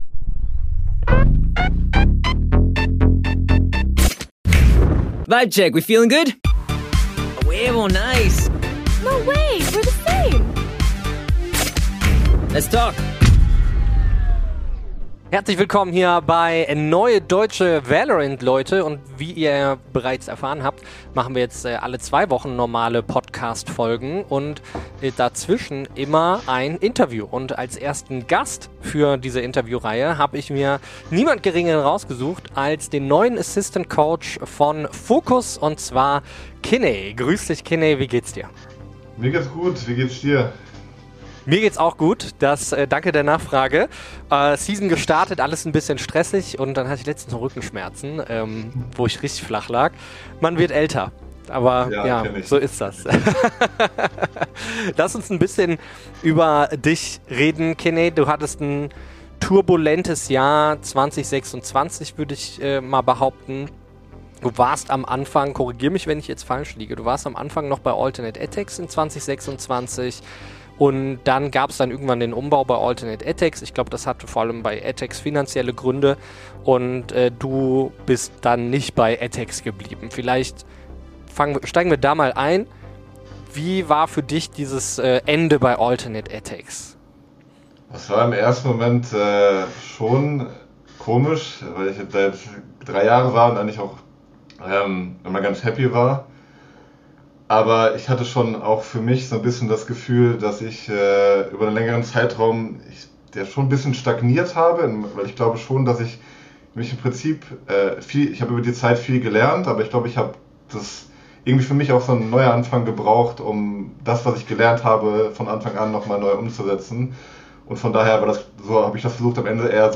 Beschreibung vor 3 Monaten Fast 3 Jahre hat es gedauert - jetzt ist unser Interview Format CLUTCH MUTE zurück und erscheint alle 2 Wochen im Wechsel mit der regulären Folge!